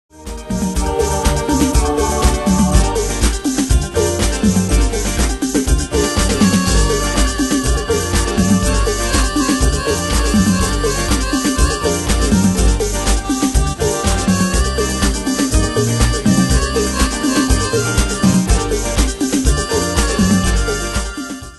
Style: Dance Année/Year: 1999 Tempo: 122 Durée/Time: 4.11
Danse/Dance: Dance Cat Id.
Pro Backing Tracks